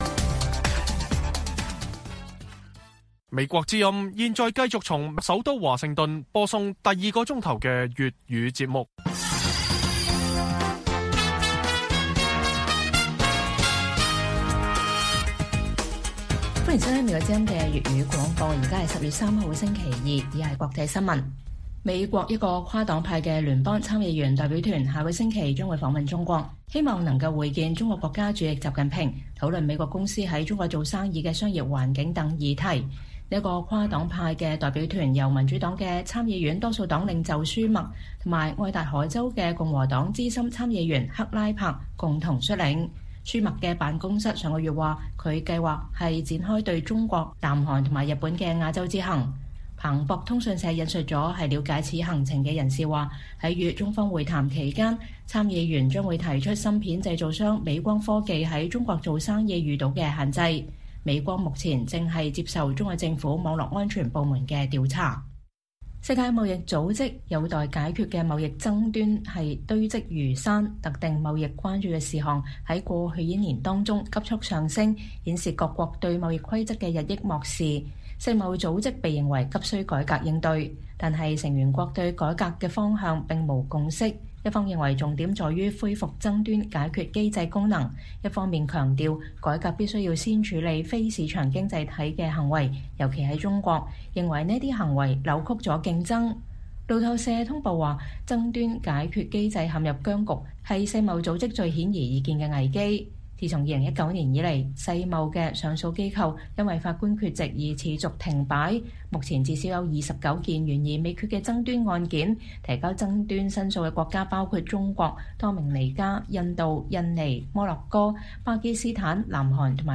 粵語新聞 晚上10-11點: 「一帶一路」邁入十週年: 東南亞是大幅受益或反招不良後果?